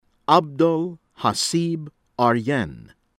ARSALA, HEDAYAT AMIN heh-dah-YAHT   ah-MEEN   ahr-sah-LAH